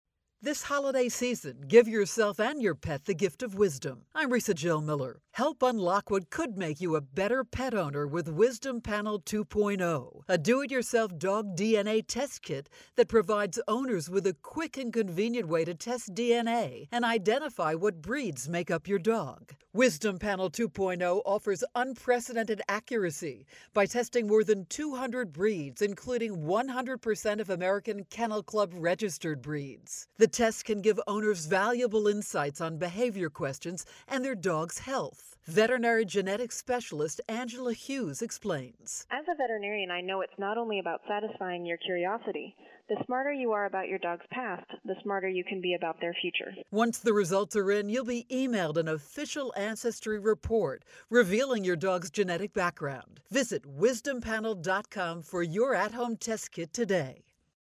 November 18, 2013Posted in: Audio News Release